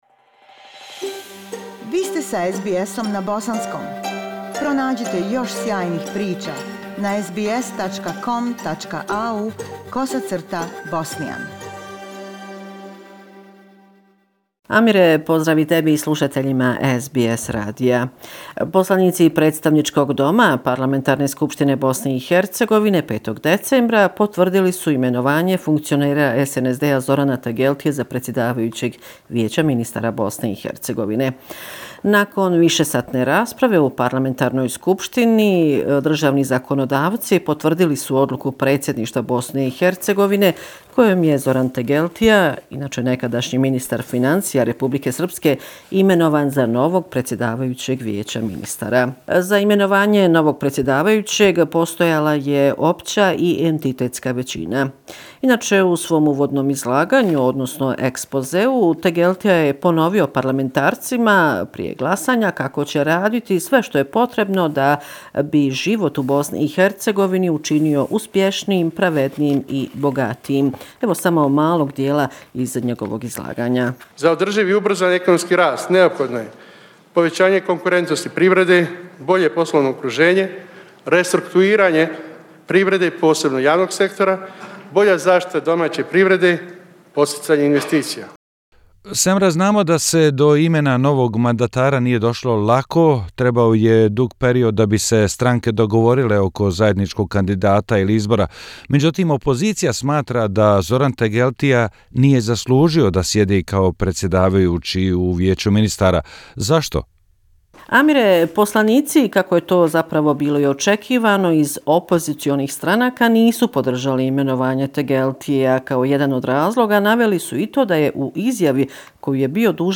Bosnia and Herzegovina - affairs in the country for the last seven day, weekly report December 7, 2019